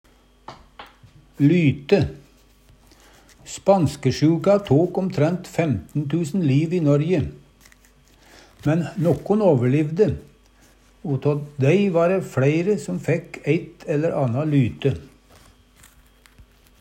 lyte - Numedalsmål (en-US)